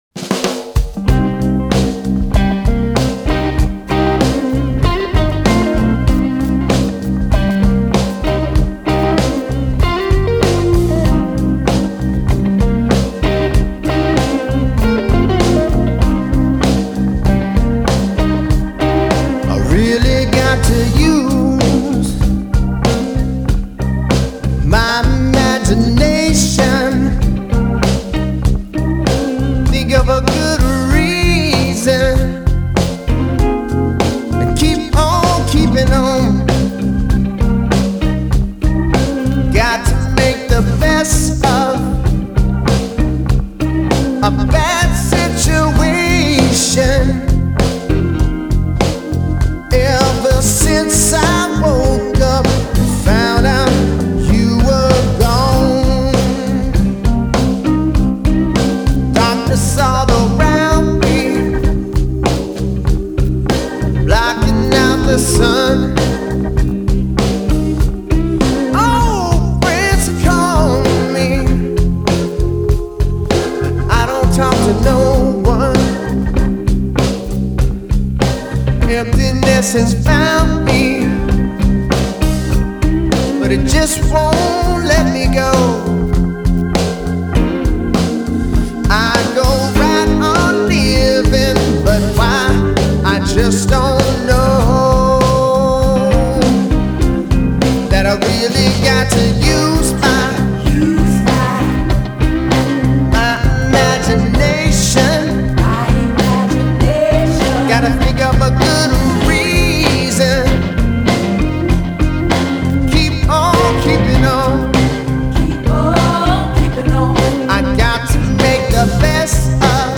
Genre: Blues, Blues Rock